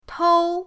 tou1.mp3